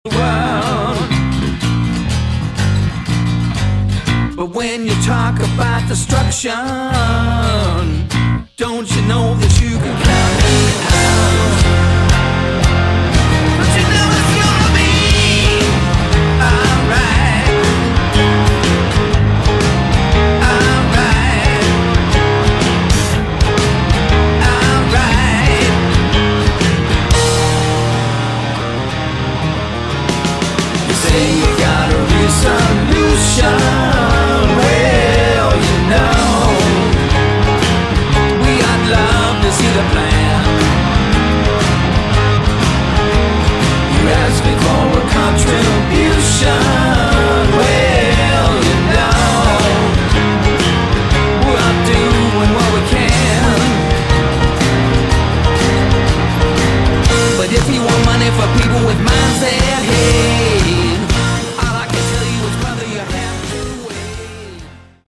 Category: Hard Rock
Drums, Percussion
Hammond B-3 Organ and Piano